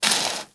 SPADE_Dig_03_mono.wav